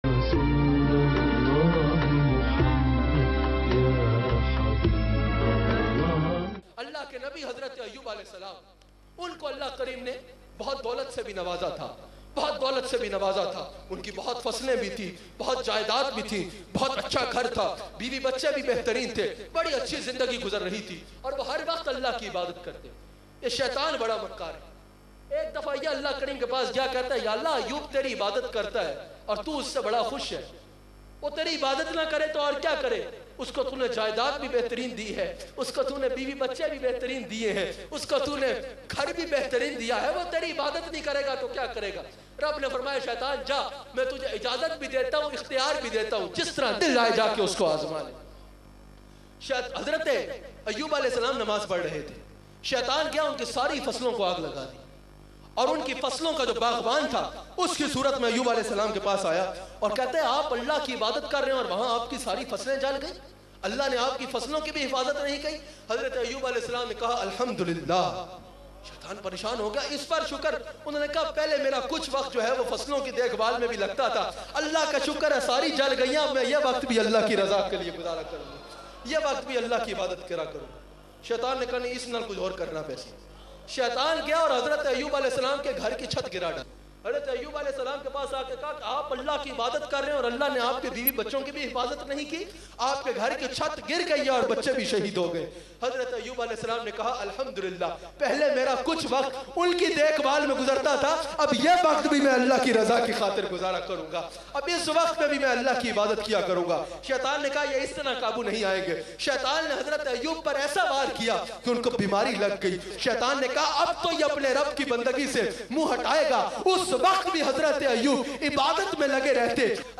Eman or Islam bayan mp3